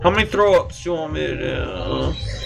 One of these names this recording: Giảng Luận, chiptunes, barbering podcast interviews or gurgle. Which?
gurgle